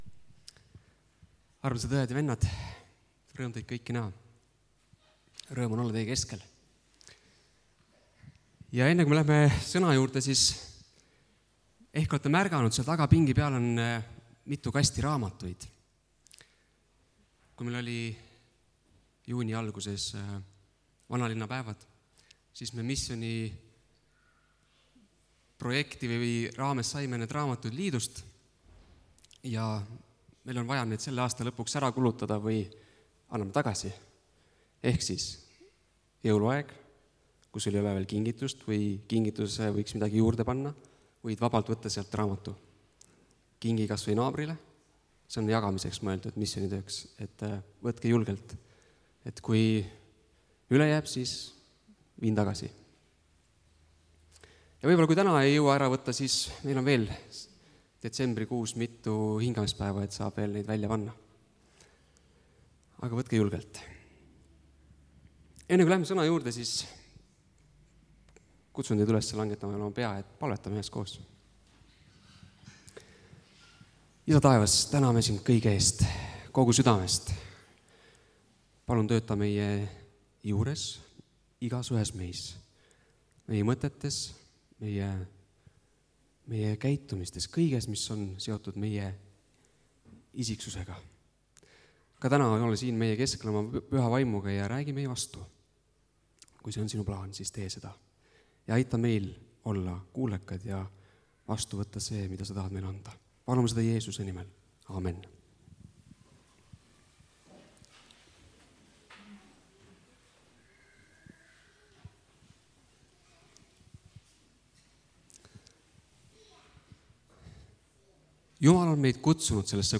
kategooria Audio / Jutlused